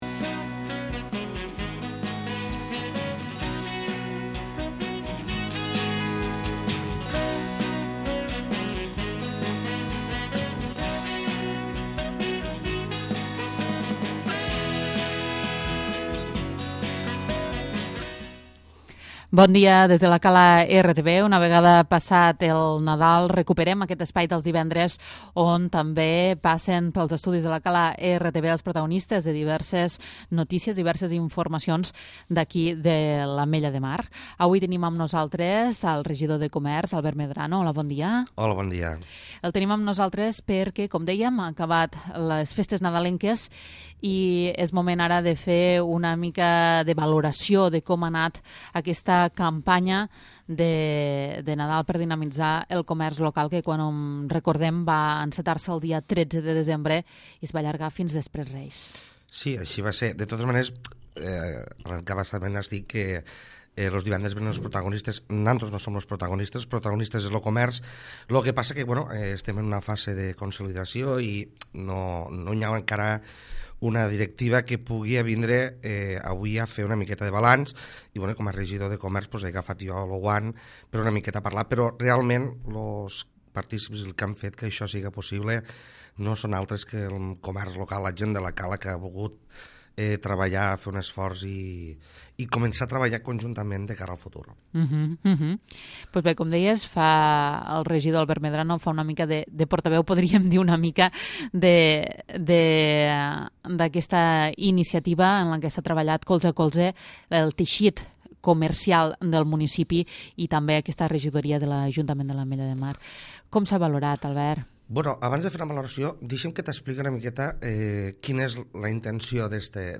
L'Entrevista
El regidor de Comerç, Albert Medrano, ens acompanya en aquest espai on hem parlat sobre la campanya nadalenca del comerç local que va tenir lloc del 13 de desembre fins després de reis.